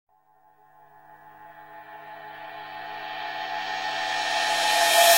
mus_cymbal.ogg